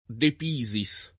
vai all'elenco alfabetico delle voci ingrandisci il carattere 100% rimpicciolisci il carattere stampa invia tramite posta elettronica codividi su Facebook De Pisis [ de p &@ i S ] cogn. — così il pittore Filippo de P. (1896-1956), con -s- sonora anche nella pn. tosc. nonostante la derivaz. da Pisa